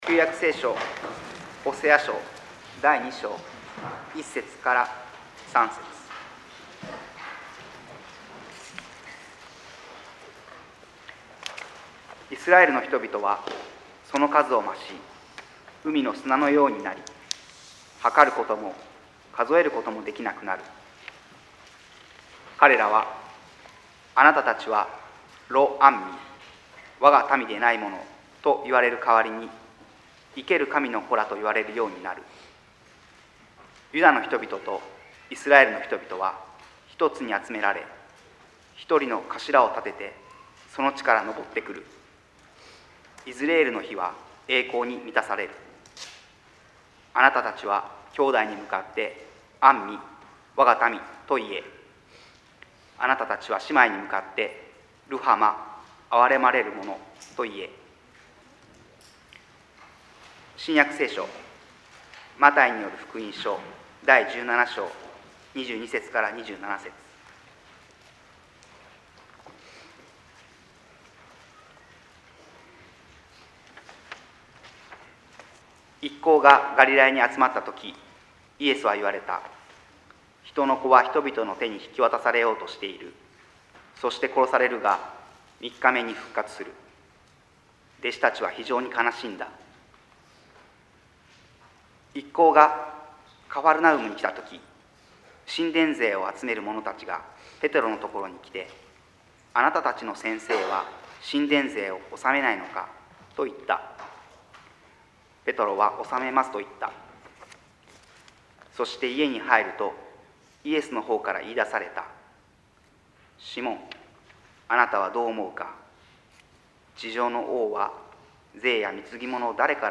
説 教 「神の子どもたち」